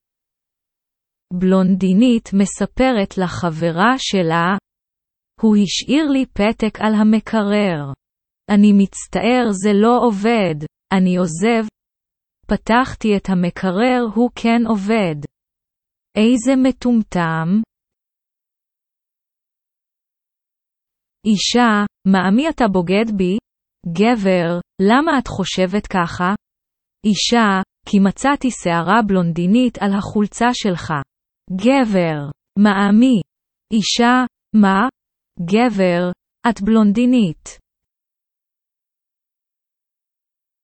Texte Hébreu lu à haute voix à un rythme lent !